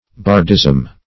Search Result for " bardism" : The Collaborative International Dictionary of English v.0.48: Bardism \Bard"ism\ (b[aum]rd"[i^]z'm), n. The system of bards; the learning and maxims of bards.
bardism.mp3